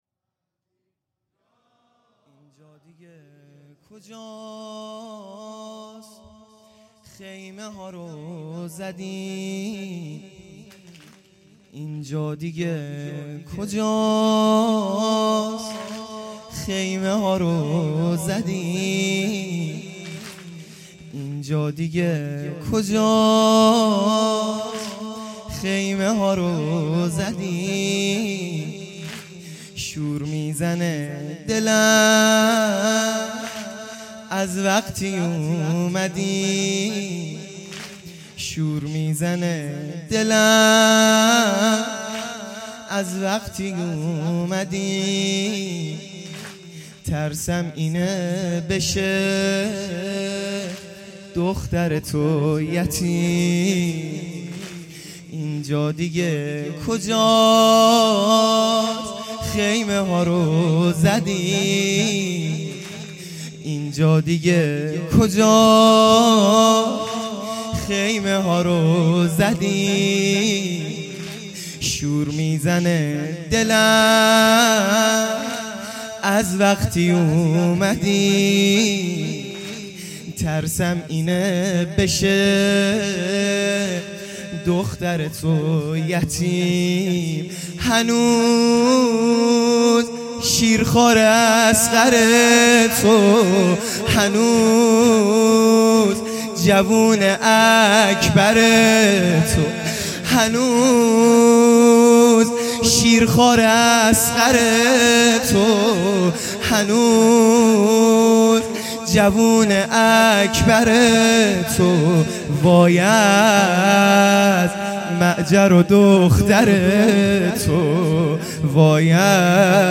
شب دوم محرم الحرام ۱۳۹۶